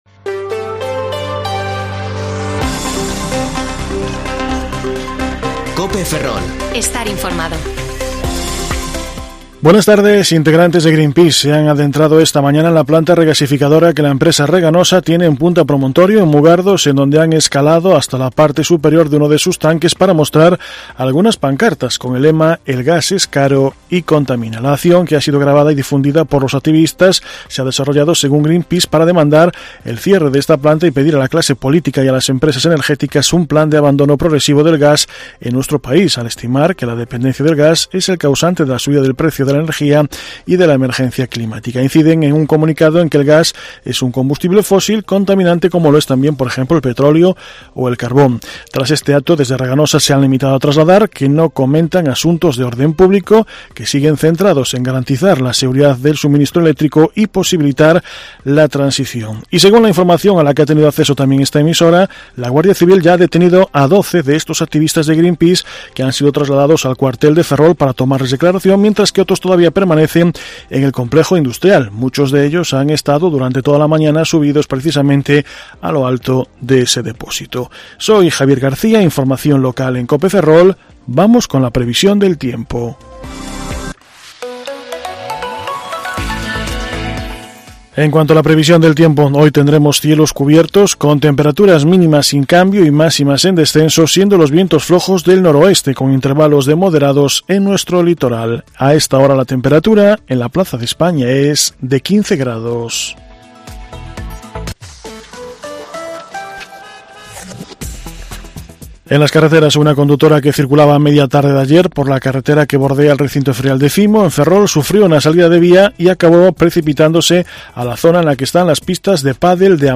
Informativo Mediodía COPE Ferrol 15/11/2021 (De 14,20 a 14,30 horas)